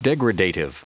Prononciation du mot degradative en anglais (fichier audio)
Prononciation du mot : degradative